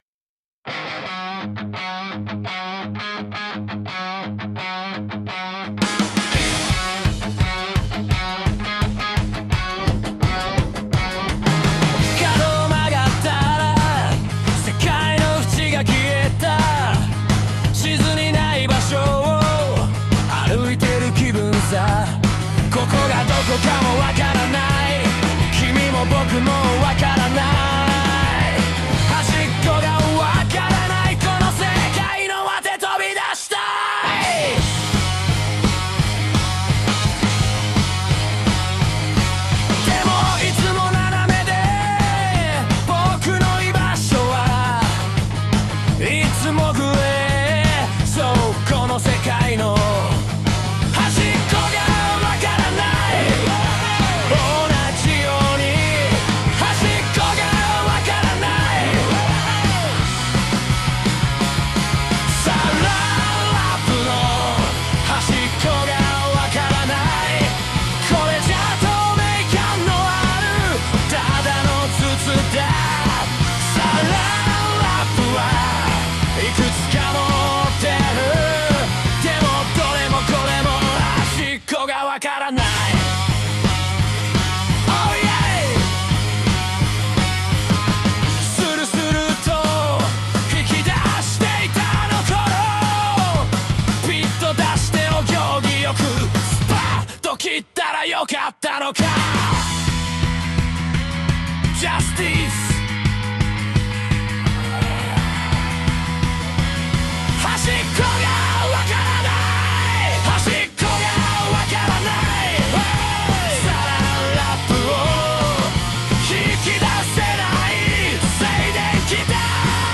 うた：suno